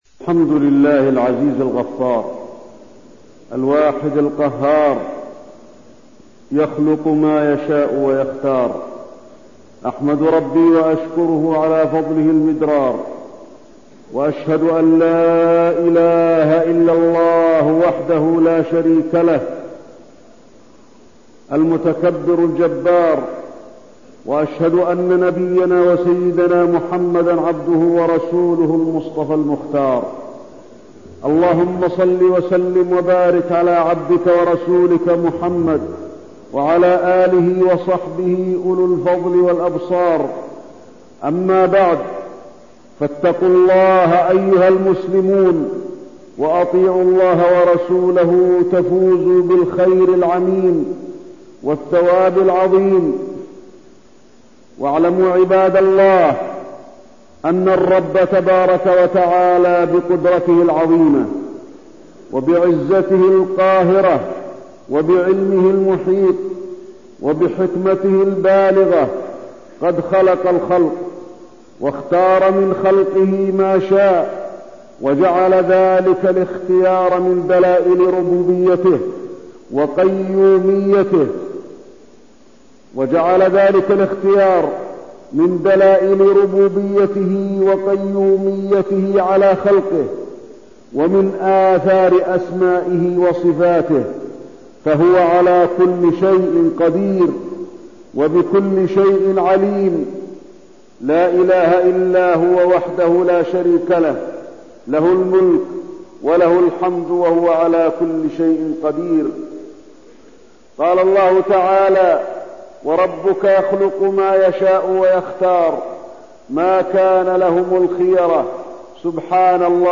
تاريخ النشر ٧ ذو الحجة ١٤١٣ هـ المكان: المسجد النبوي الشيخ: فضيلة الشيخ د. علي بن عبدالرحمن الحذيفي فضيلة الشيخ د. علي بن عبدالرحمن الحذيفي فضل عشر ذي الحجة